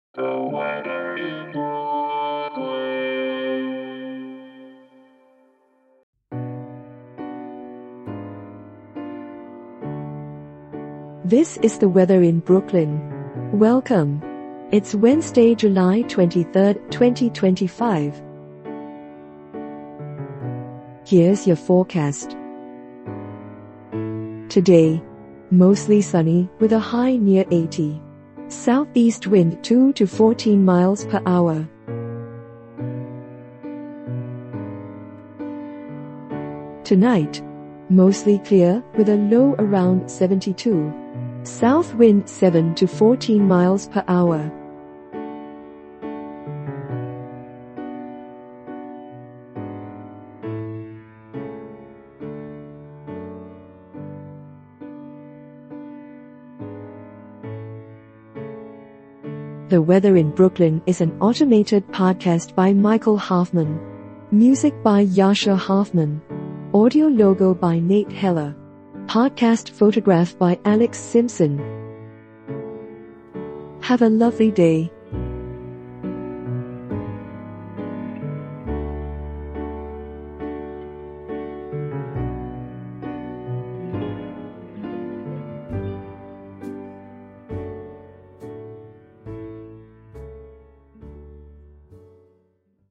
is generated automatically.